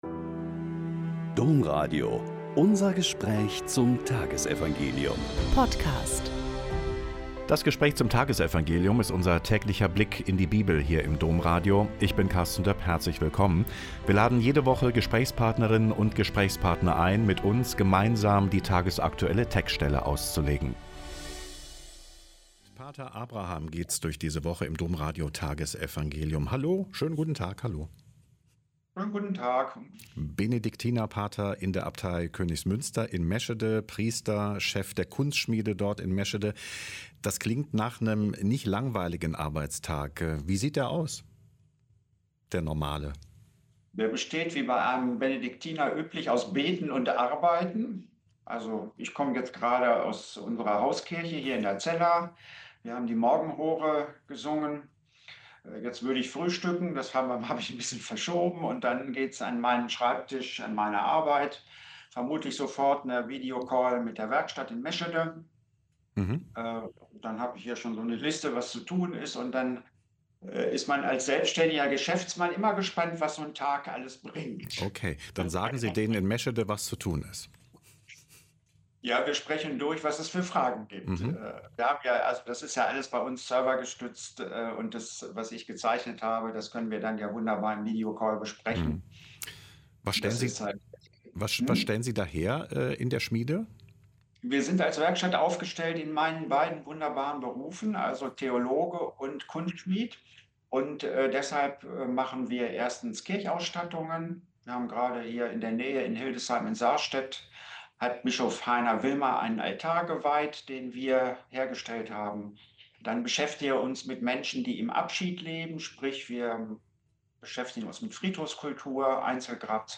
Lk 21,5-11 - Gespräch